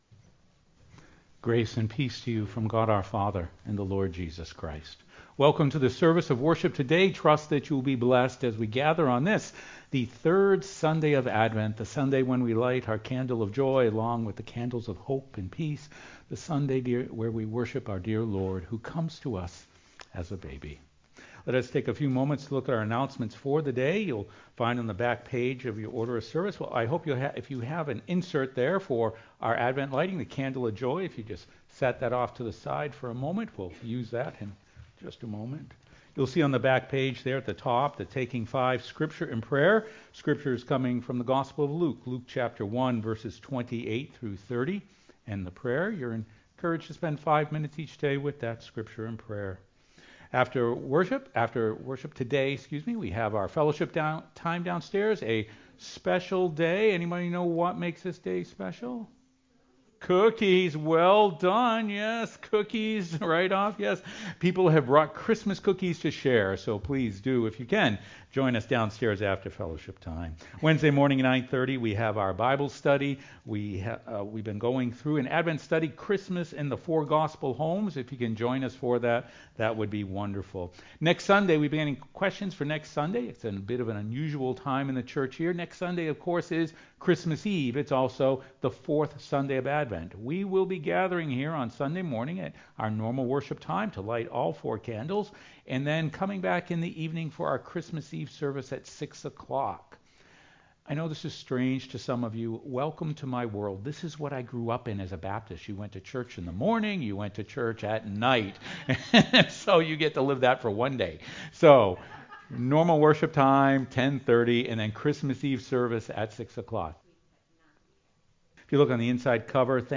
sermon-2.mp3